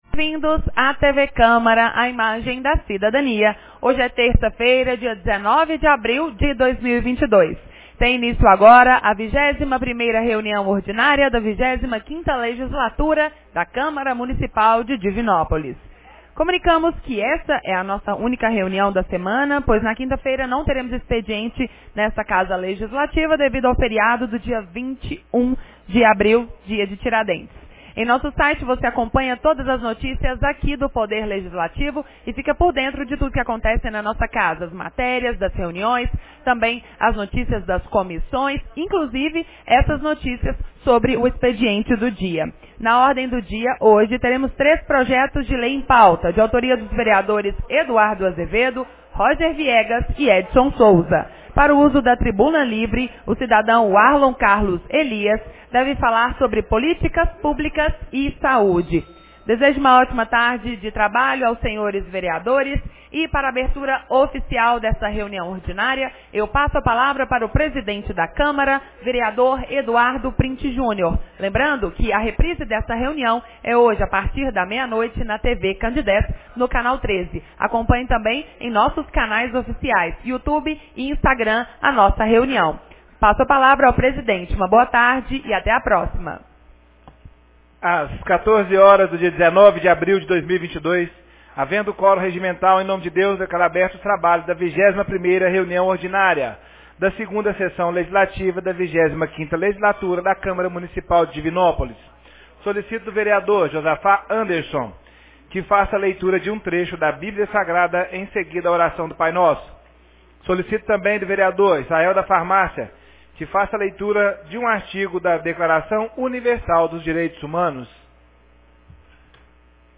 21ª Reunião Ordinária 19 de abril de 2022